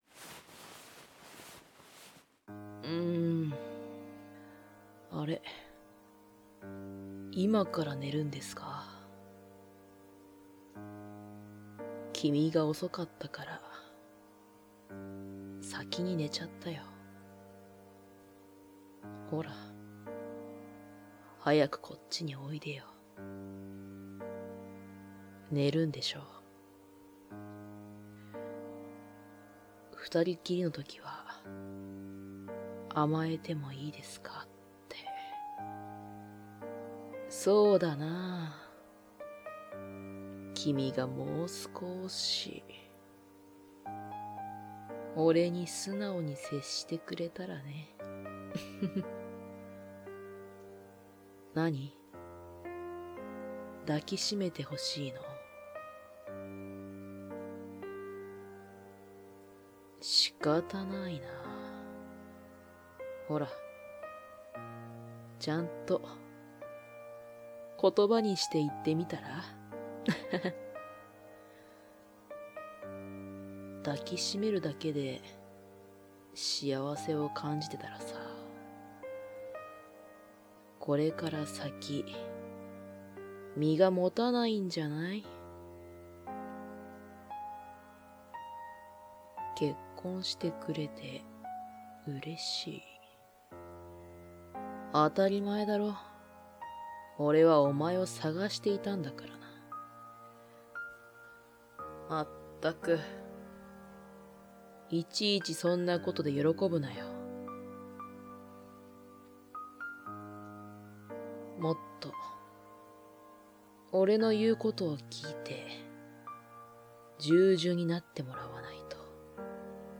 纯爱/甜蜜 温馨 治愈 皆大欢喜 女性视角 黑发 王子大人/王子系 女性向 浪漫 乙女向